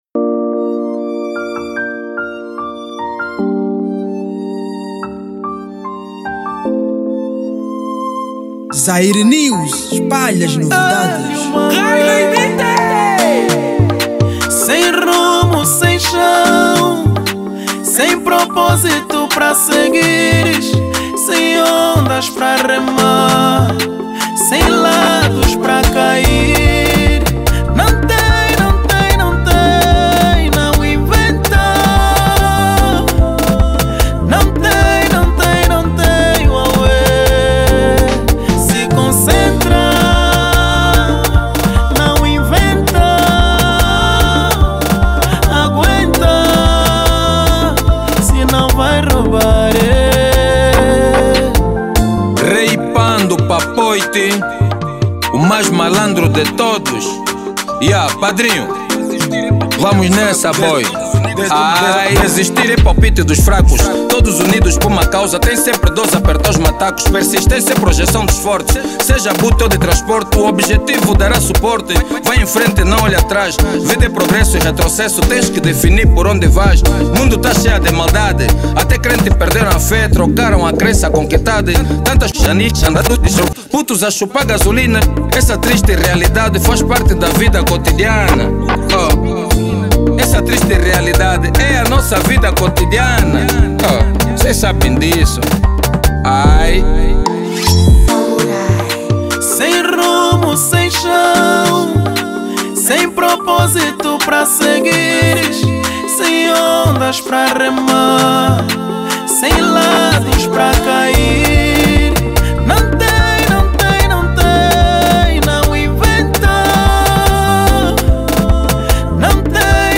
| Kuduro